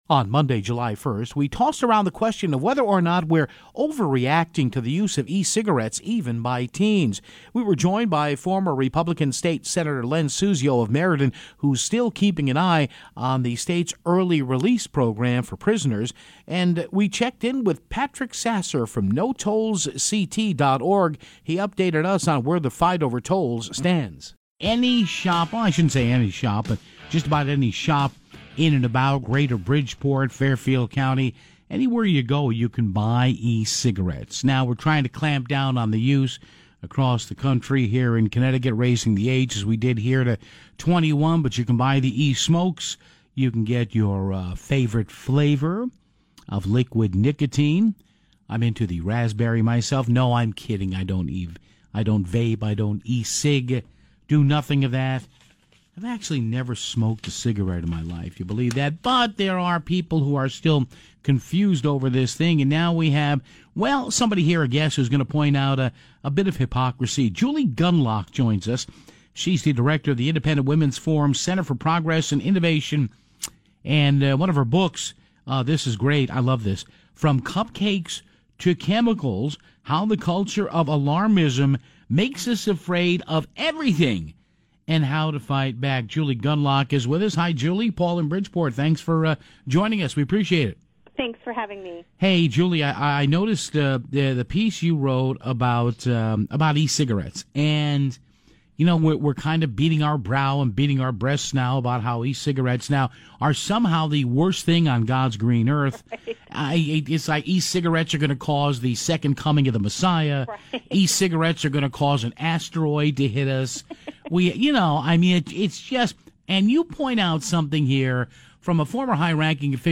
Then, former State Senator Len Suzio joins the program to discuss his take on the state’s Early Release Program.